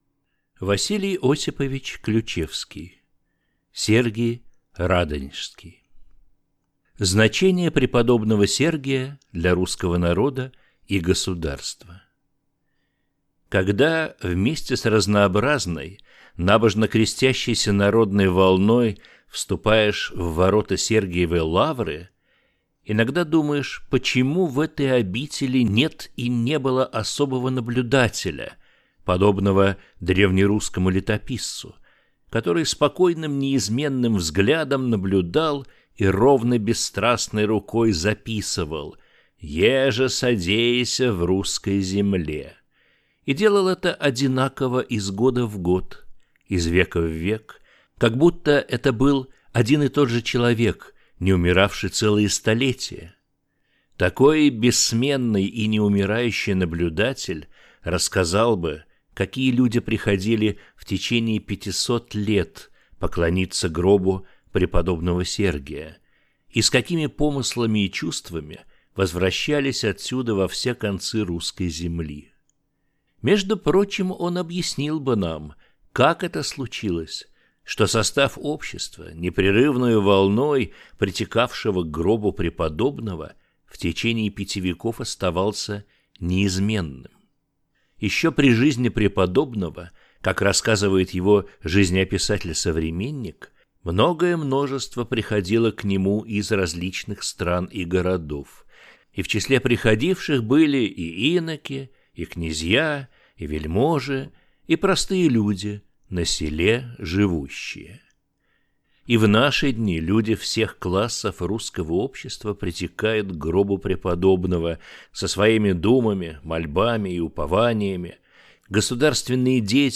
Аудиокнига Сергий Радонежский | Библиотека аудиокниг